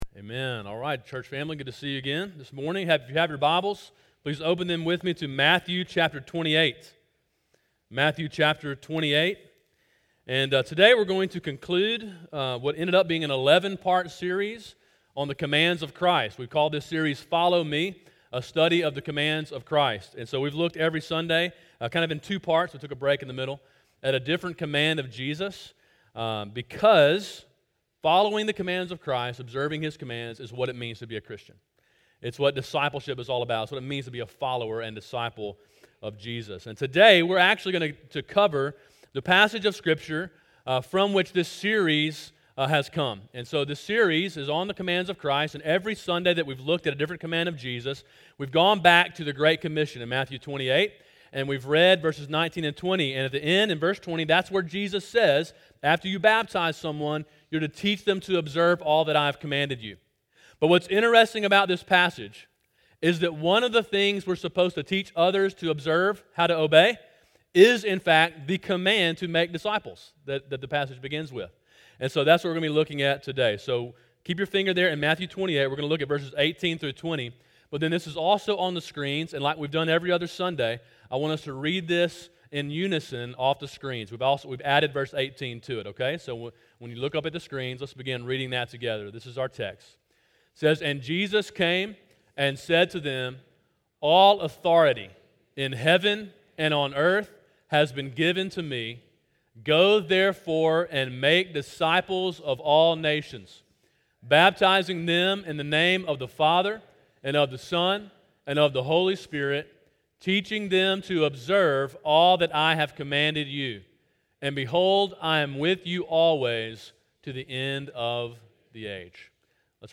Sermon: “Make Disciples” (Matthew 28:18-20)